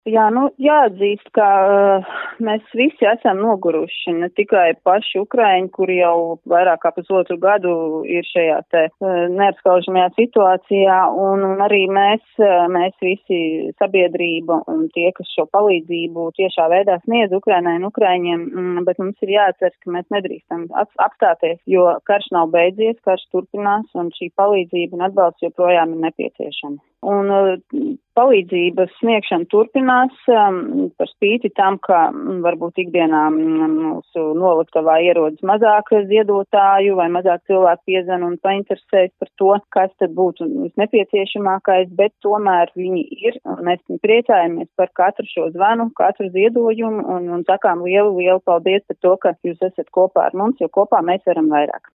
RADIO SKONTO Ziņās par atbalstu Ukrainai šobrīd